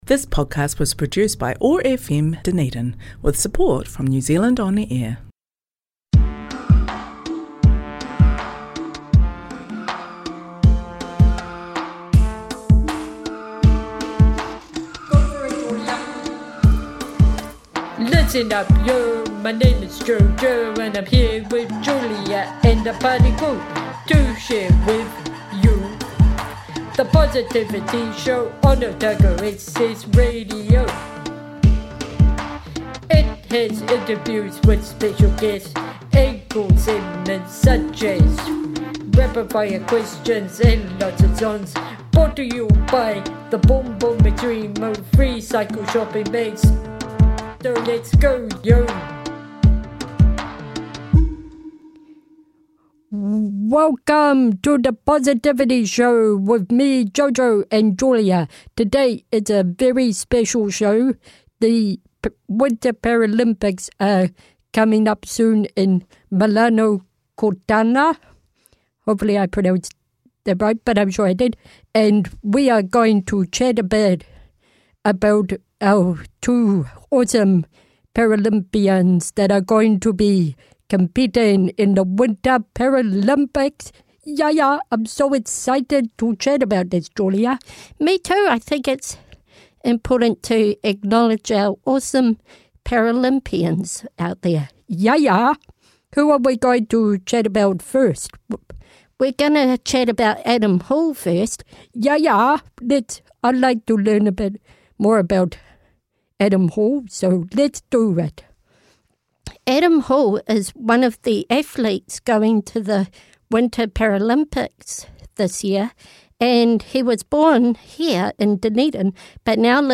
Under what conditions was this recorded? Broadcast on Otago Access Radio